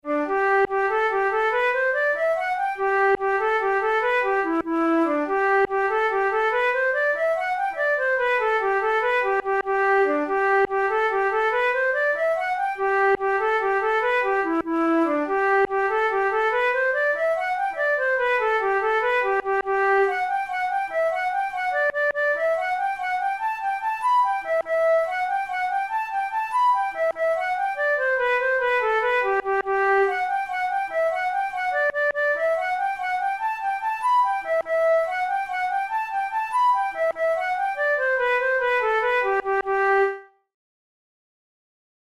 InstrumentationFlute solo
KeyG major
Time signature6/8
Tempo96 BPM
Jigs, Traditional/Folk
Traditional Scottish jig